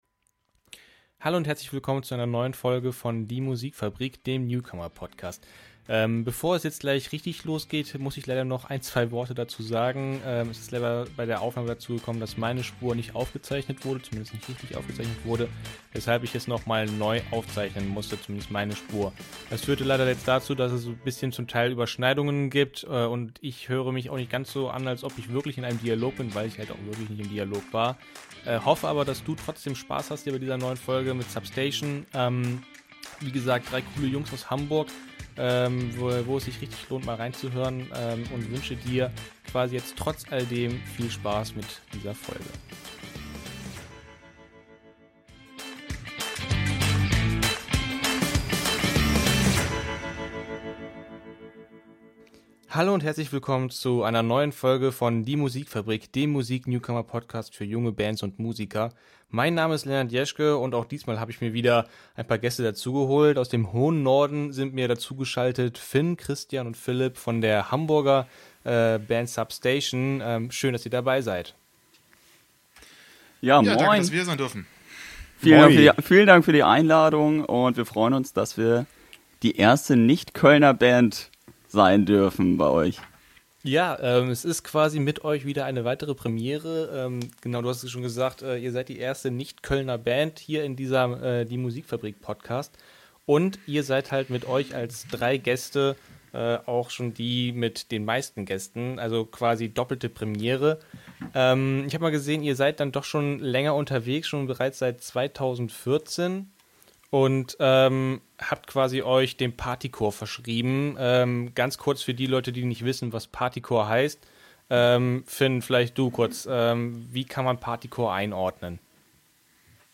Ein echt lustige Runde mit vielen Fragen und interessanten Antworten.